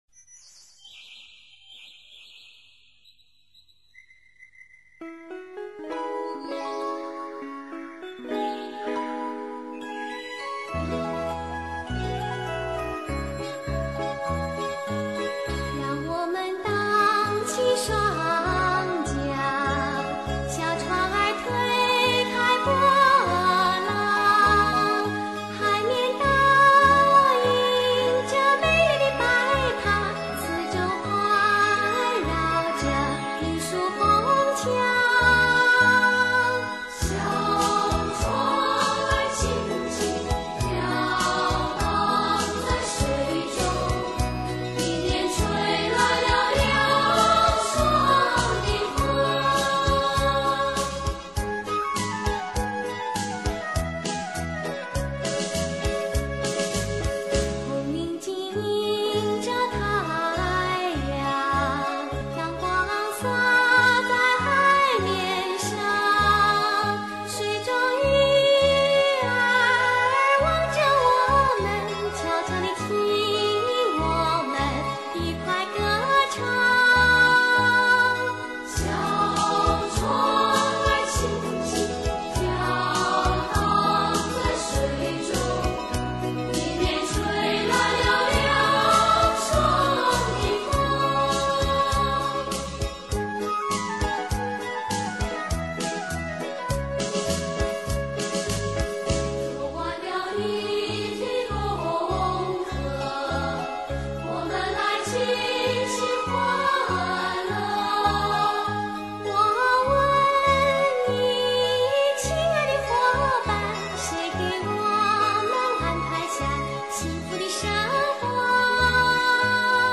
儿歌